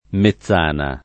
Mezzana [mezz#na o